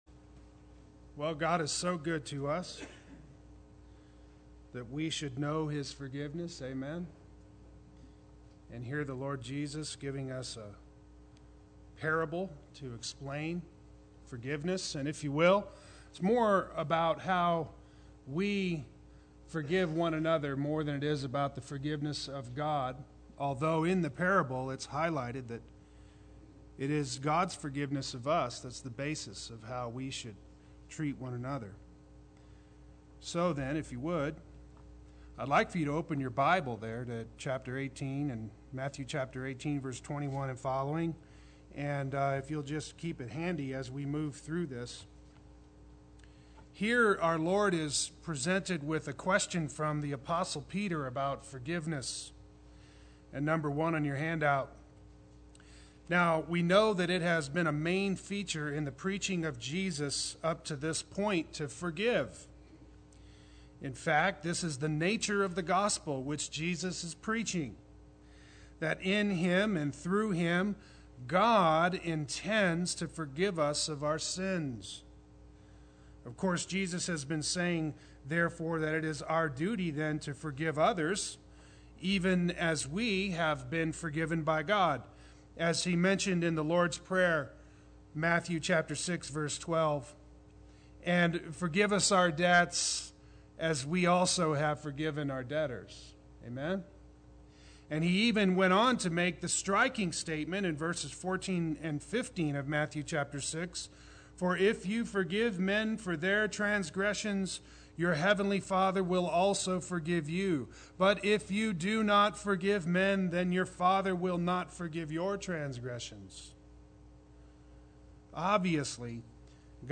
Play Sermon Get HCF Teaching Automatically.
-Seventy Times Seven Sunday Worship